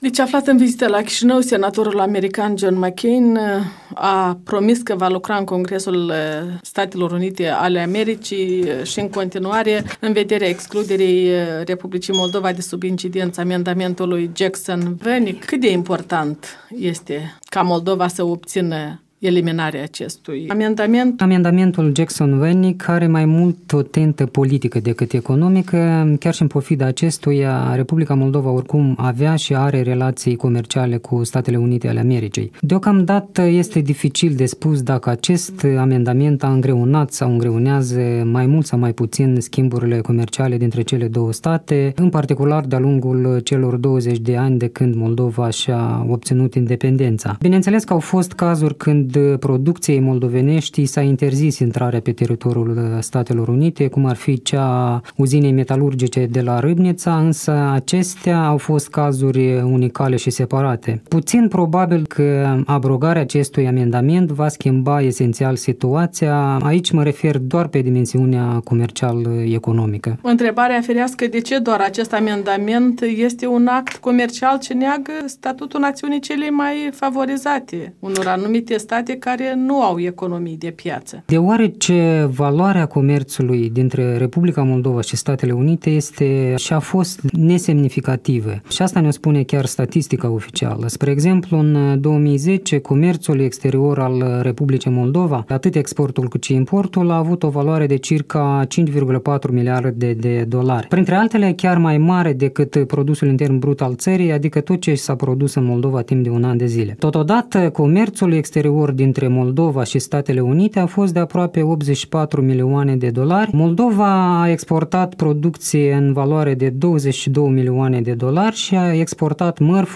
Un punct de vedere